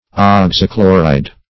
Search Result for " oxychloride" : The Collaborative International Dictionary of English v.0.48: Oxychloride \Ox`y*chlo"ride\, n. [Oxy (a) + chloride.]